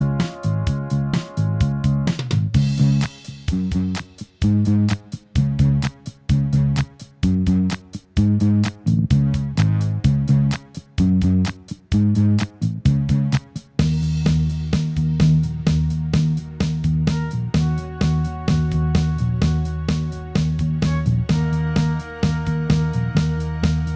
Minus Guitars Pop (1970s) 2:57 Buy £1.50